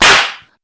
slap233.mp3